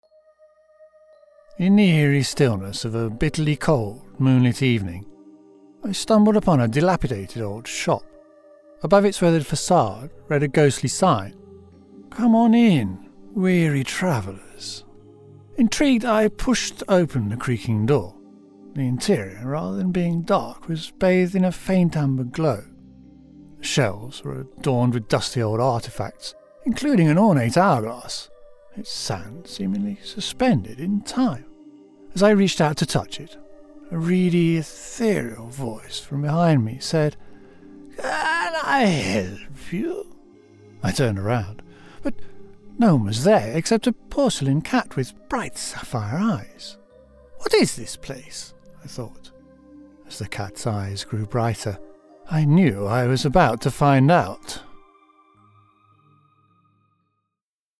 0513Audiobook_-_Eerie_Shop.mp3